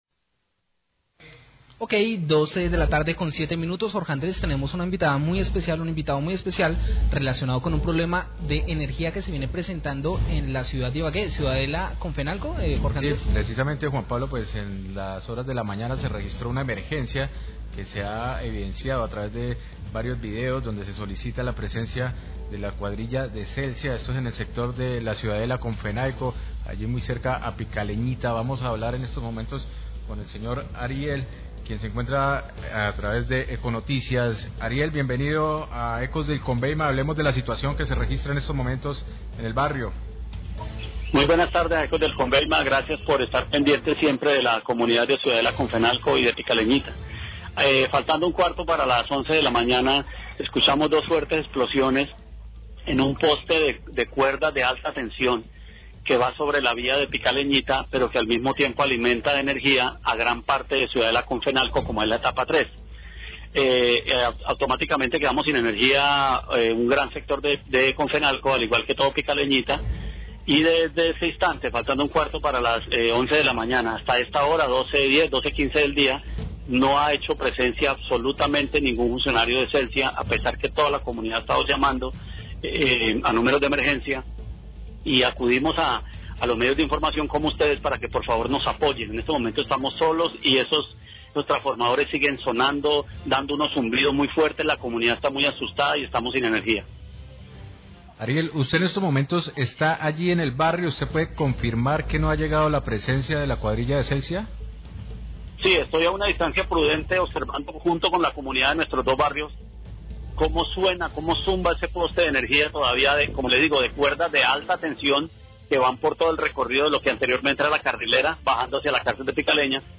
Radio
reporte oyente